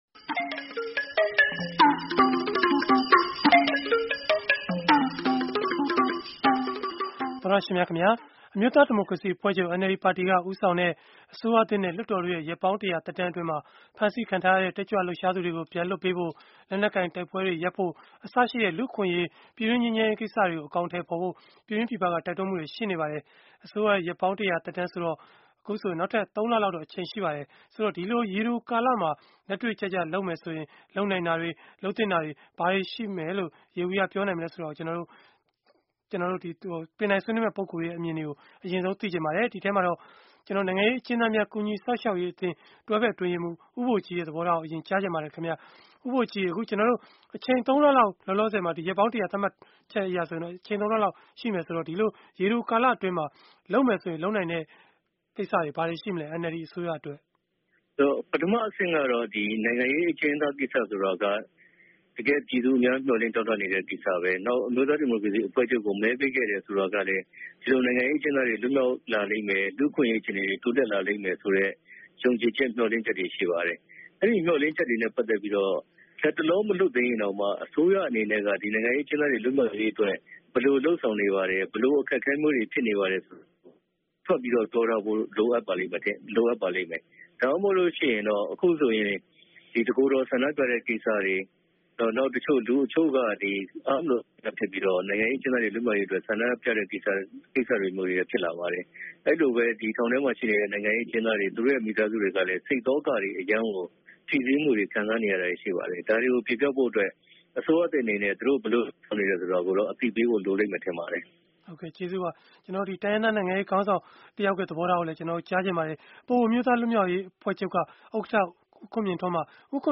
NLD အစိုးရရဲ့ ရက်ပေါင်း ၁၀၀ သက်တမ်းအတွင်း နိုင်ငံရေး၊ လူမှုရေး၊ စီးပွားရေး ဘယ်ကဏ္ဍတွေမှာ ဦးစားပေး ဖြေရှင်းနိုင်မလဲ ဆိုတာကို အင်္ဂါနေ့ည တိုက်ရိုက်လေလှိုင်း အစီအစဉ်မှာ ဆွေးနွေးထားပါတယ်။